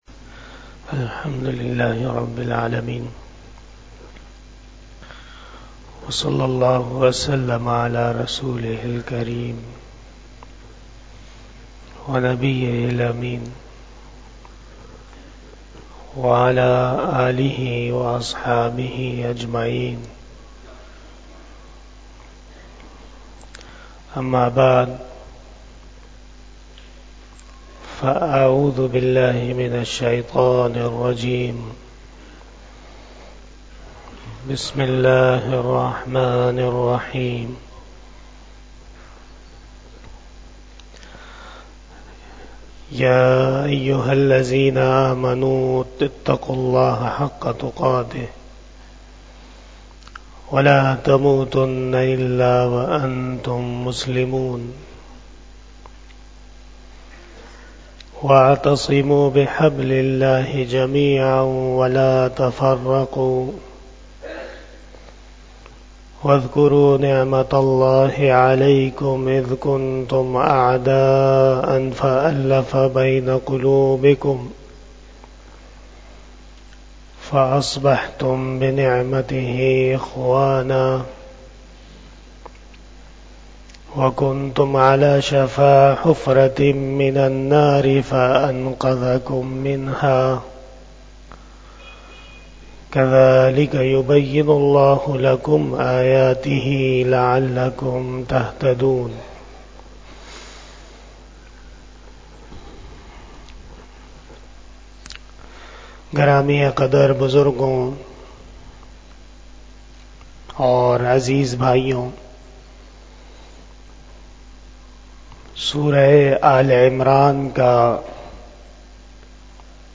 02 Shab E Jummah Bayan 11 January 2024 (29 Jamadi Us Sani 1445 HJ)